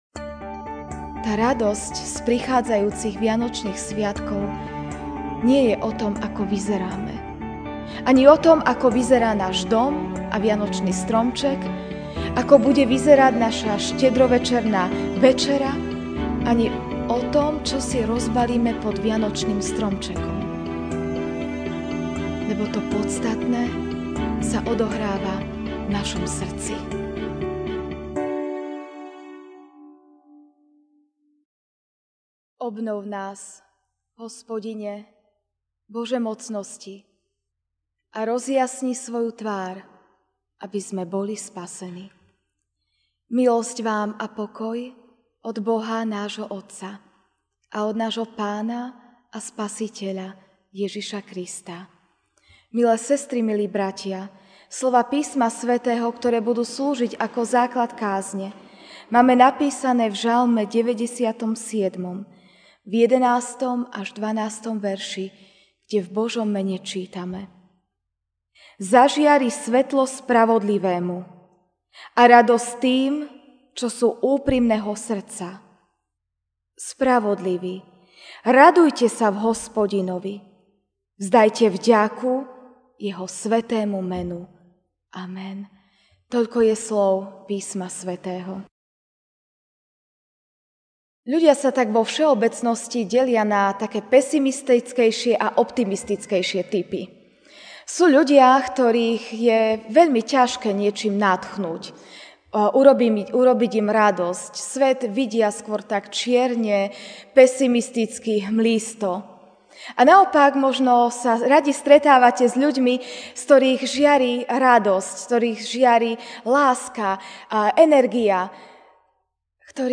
Ranná kázeň: Radostné svedectvo o blížiacom sa Pánovi (Žalm 97, 11-12) Zažiari svetlo spravodlivému a radosť tým, čo sú úprimného srdca.